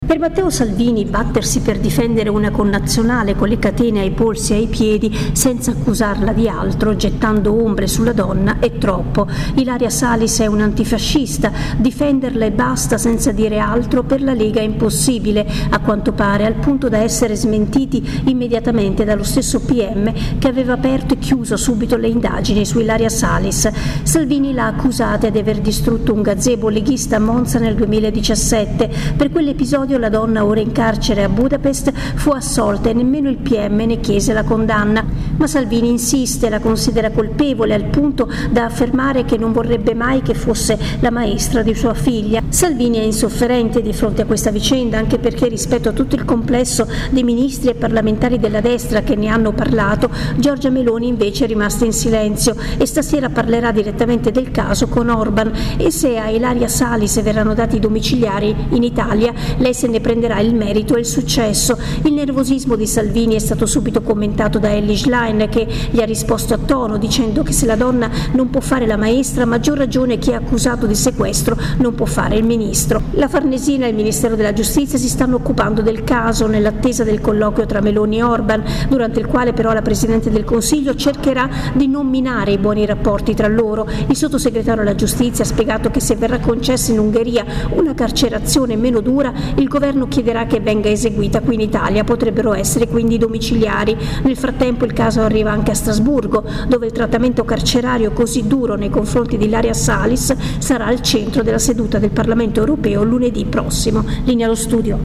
Da Roma